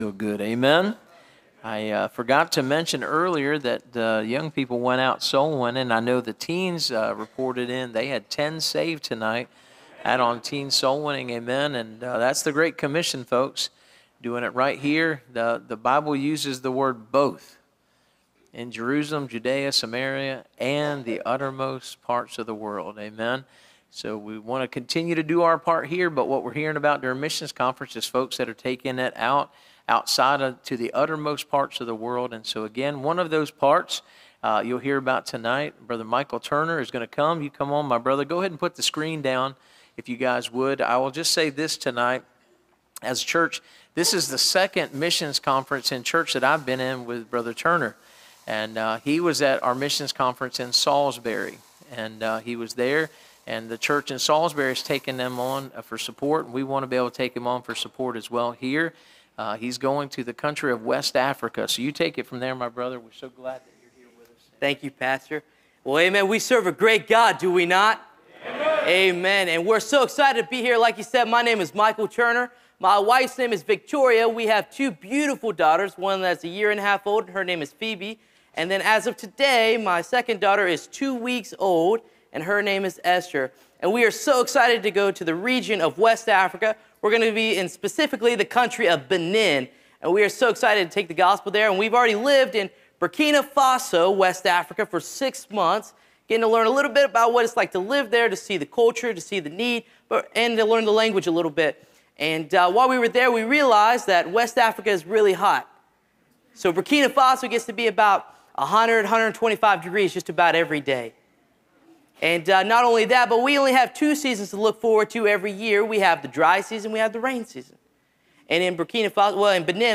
Series: (Missions Conference 2025)
Preacher